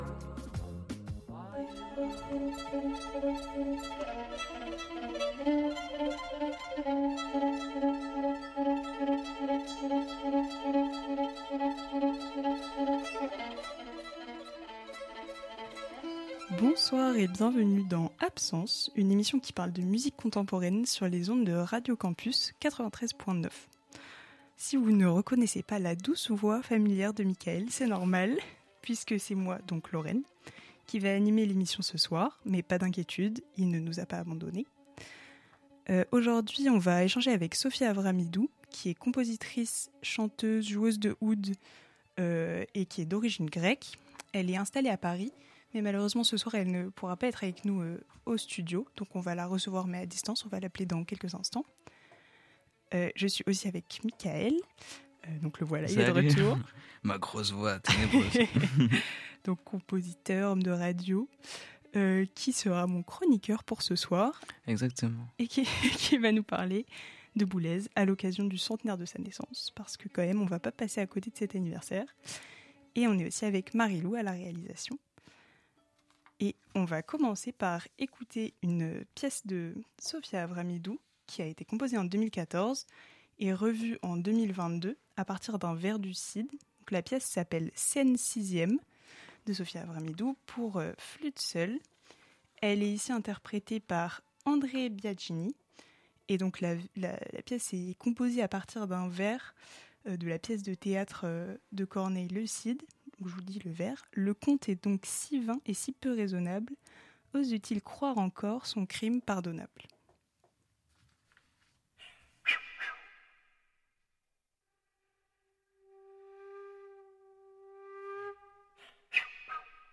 au téléphone ...
Classique & jazz